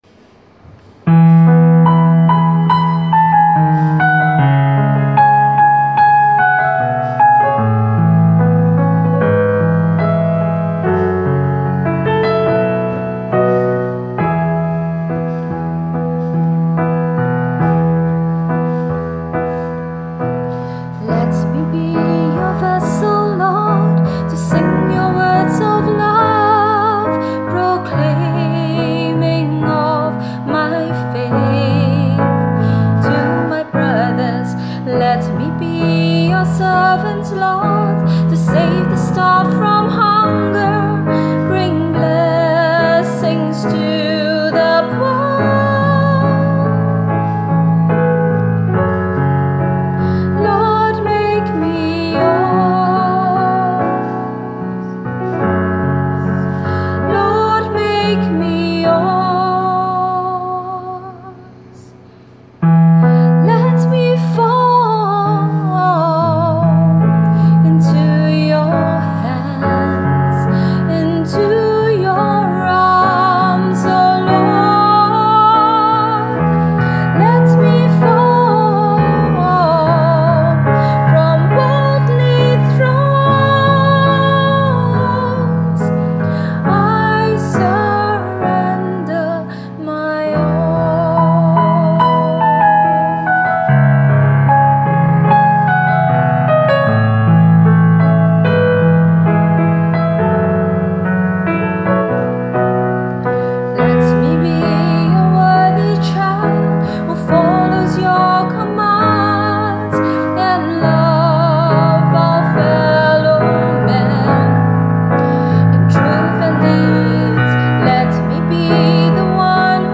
Liturgical: Offertory Song